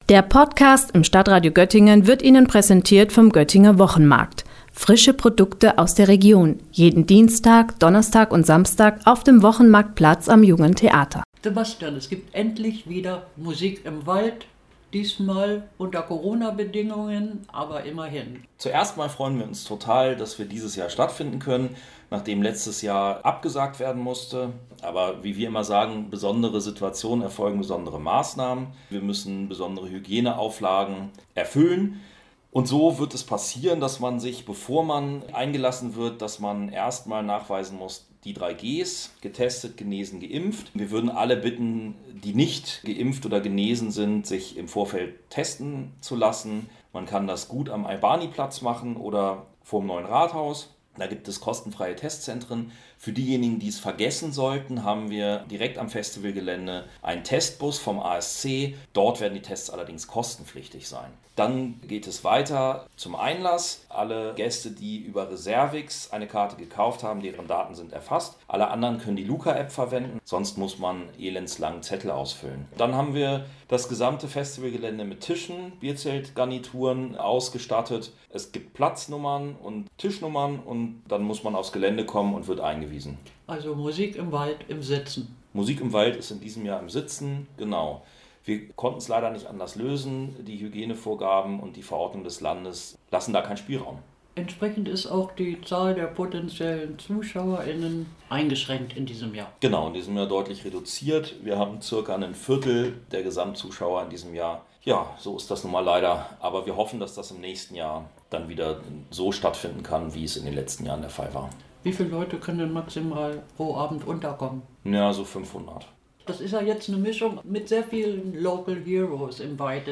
Beiträge > „KWP – Musik im Wald“: Gespräch